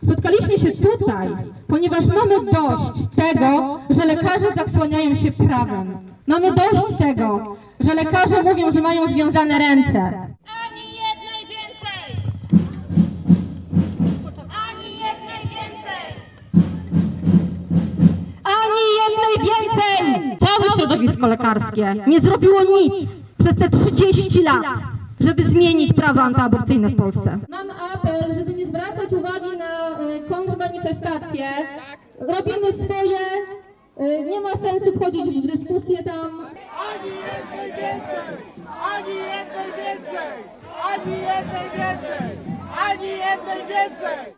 Protest przed Okręgową Izbą Lekarską
Protest odbył się przed siedzibą Okręgowej Izby Lekarskiej.